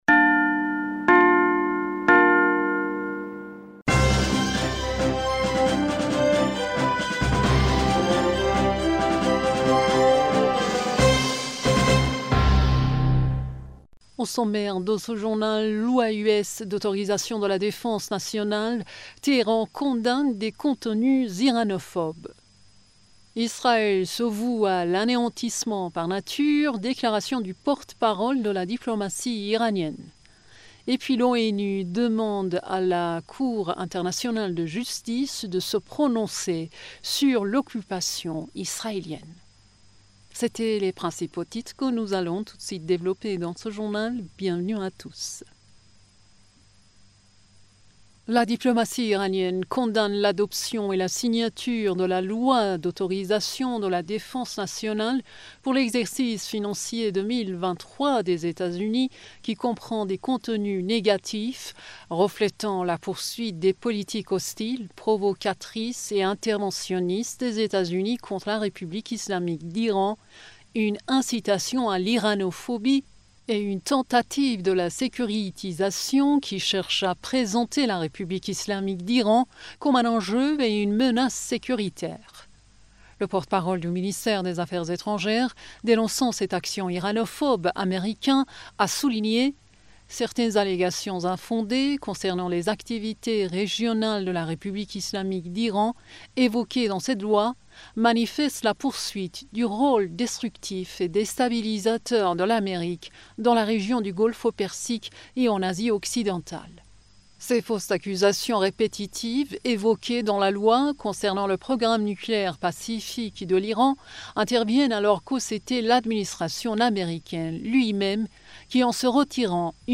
Bulletin d'information du 31 Décembre